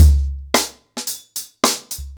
HarlemBrother-110BPM.29.wav